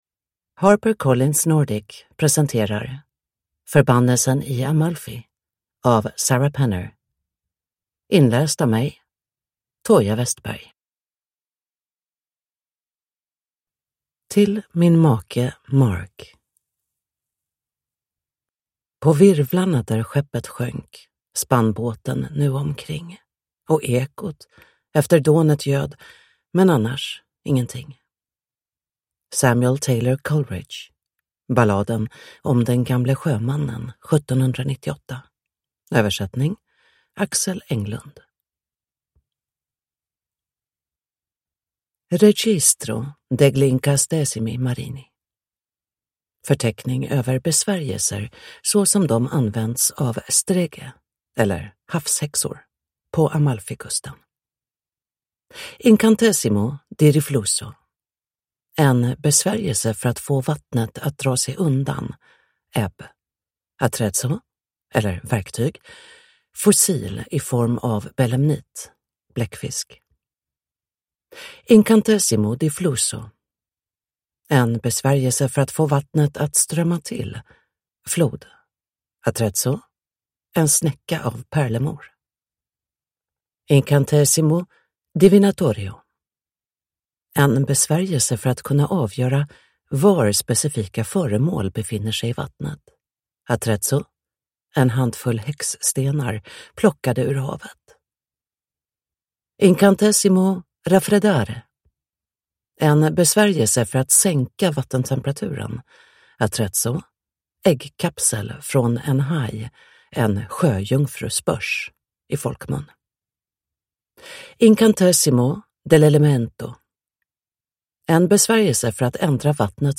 Förbannelsen i Amalfi – Ljudbok